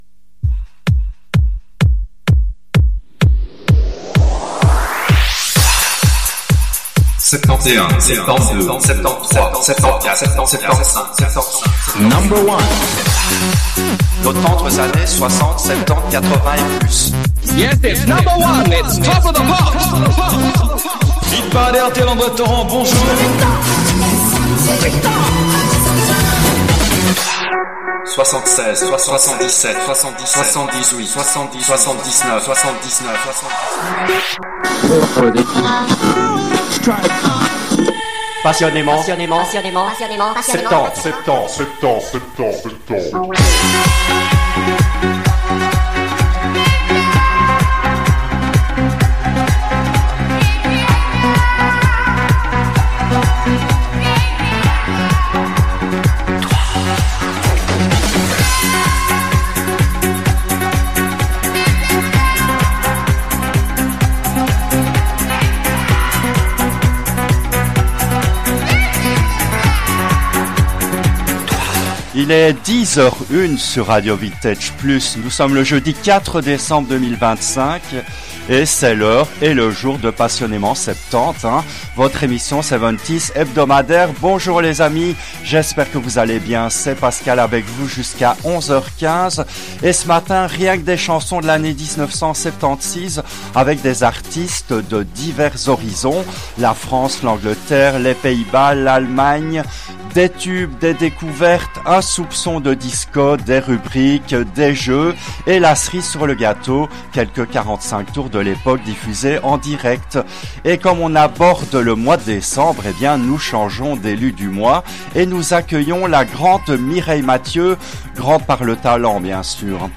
Cette émission a été diffusée en direct le jeudi 23 novembre 2023 à 10h depuis les studios belges de RADIO RV+.